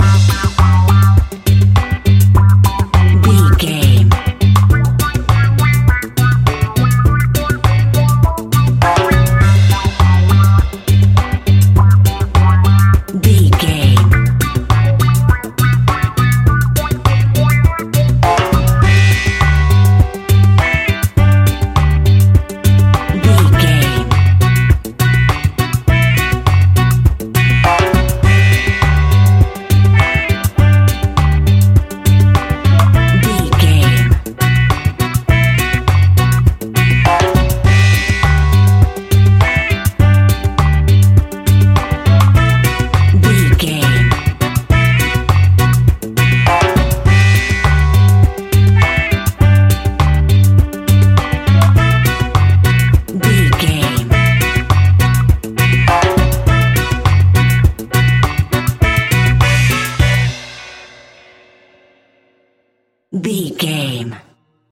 Classic reggae music with that skank bounce reggae feeling.
Aeolian/Minor
instrumentals
laid back
chilled
off beat
drums
skank guitar
hammond organ
percussion
horns